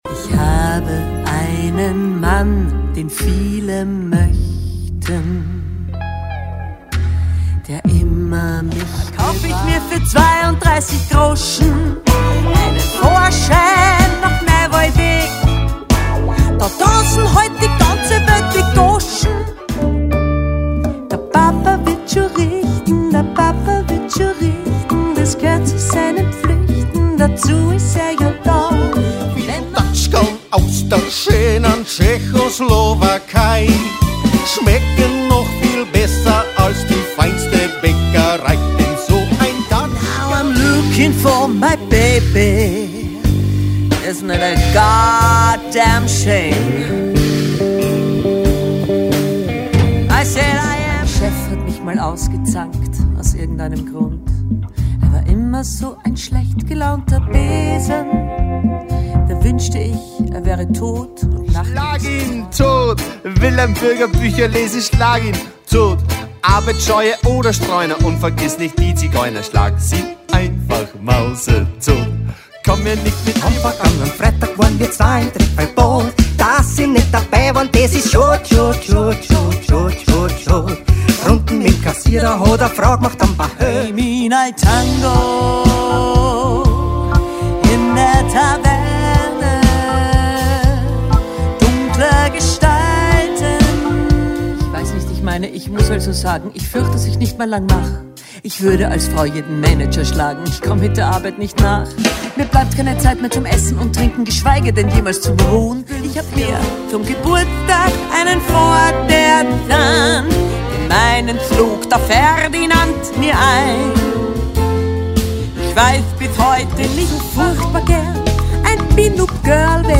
Kabarett-Lieder  der 50er Jahre,
hier hören Sie ein kurzes Stück von jedem Lied